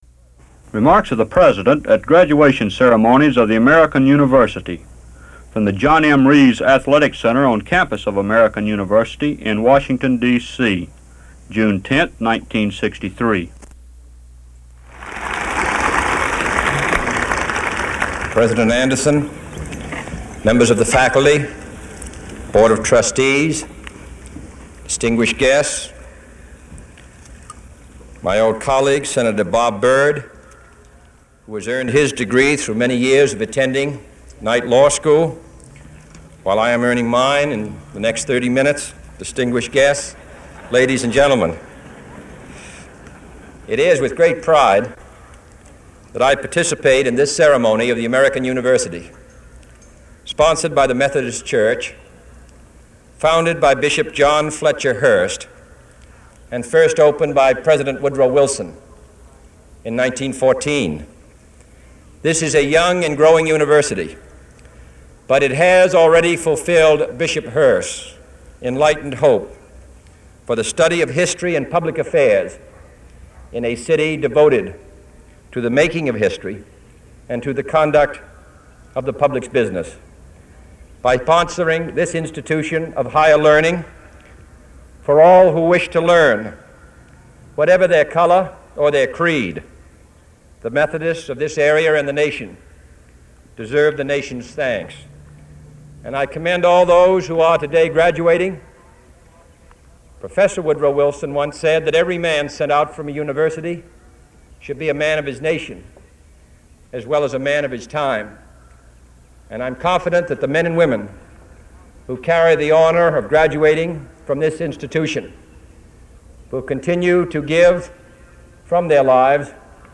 JFK Commencement Address at American University, June 10, 1963: History Will Not Absolve Us